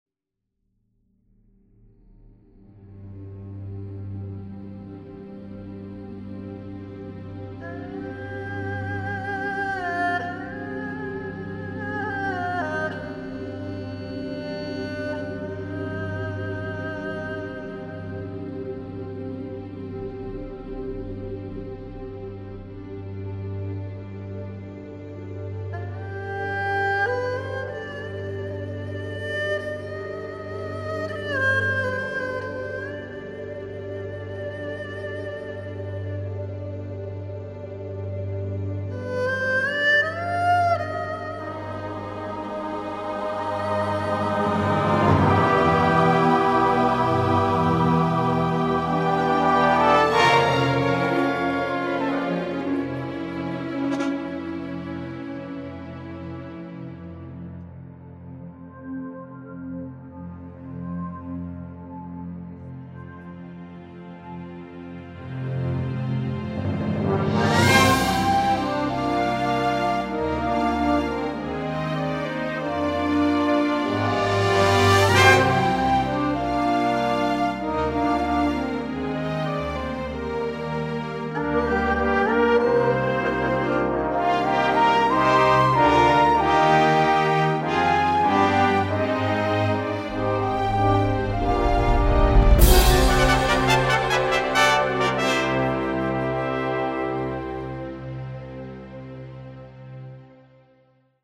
Orchestral: